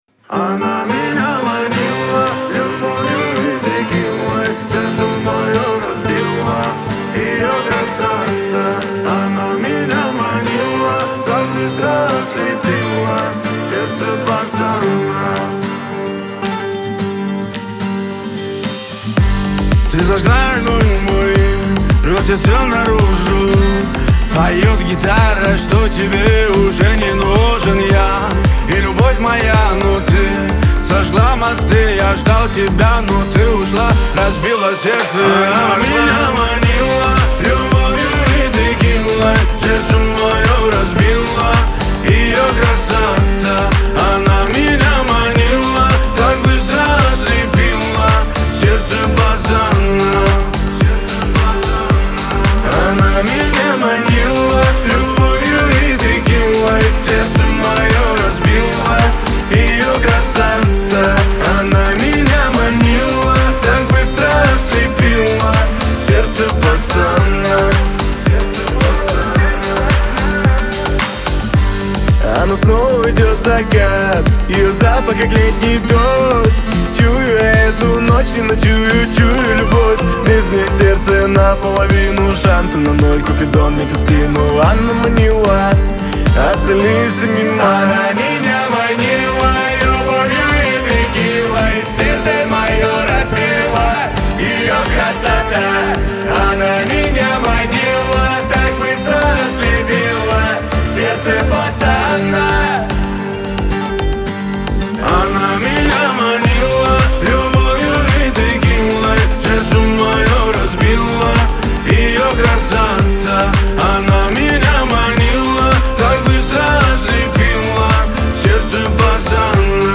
pop
эстрада
дуэт